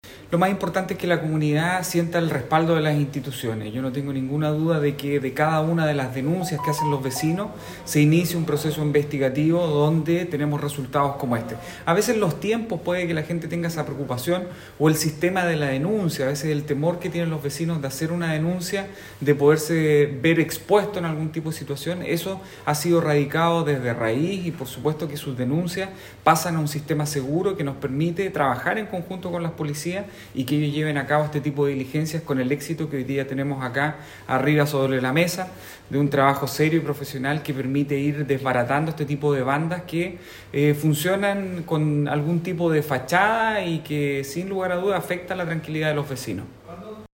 AUDIO : ALCALDE DE COQUIMBO